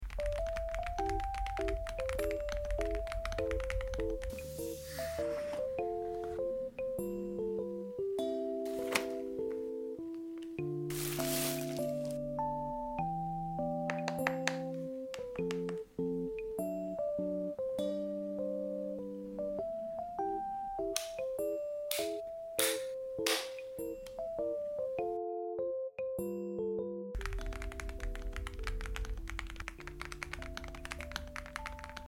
another great low profile keyboard sound effects free download
the new switches sound a little bit more dry and thin if that makes sense, but nothing too crazy.